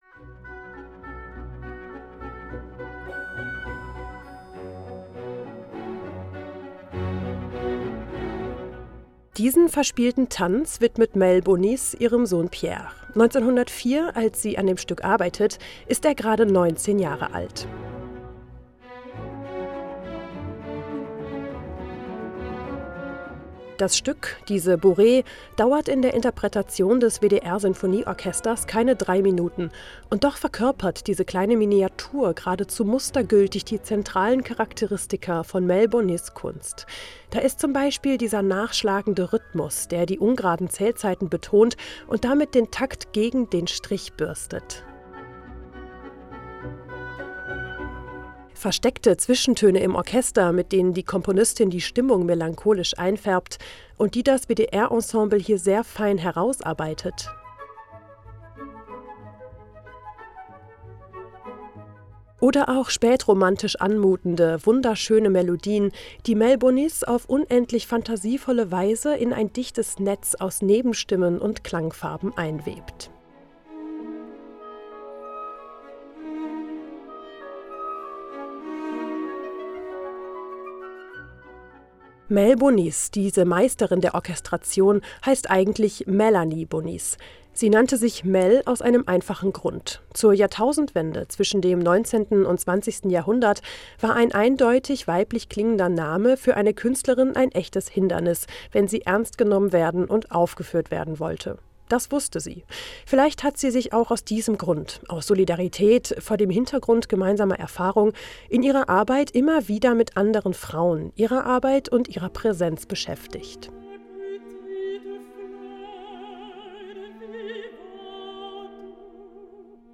Album-Tipp